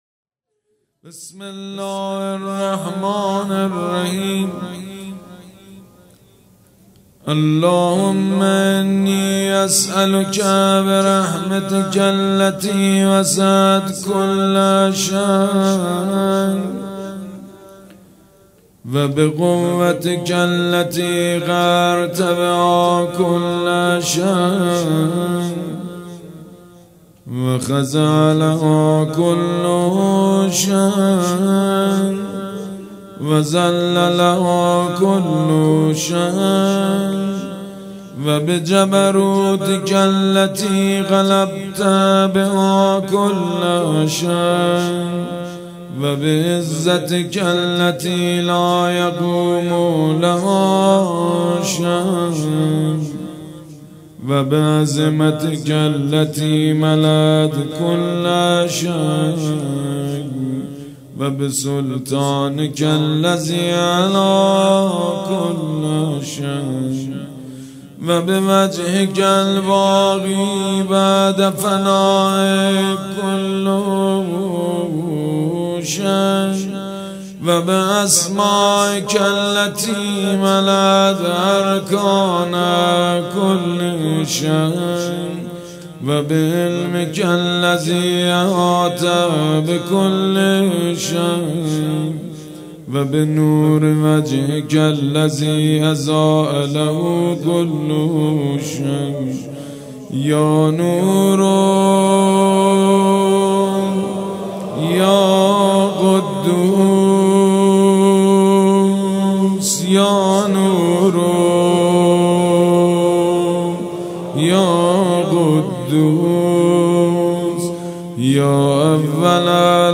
مراسم مناجات شب بیست و چهارم ماه رمضان پنجشنبه ۱۶ اردیبهشت ۱۴۰۰ حسینیه ریحانة‌الحسین(س)
سبک اثــر مناجات